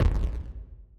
ShellExplosion.wav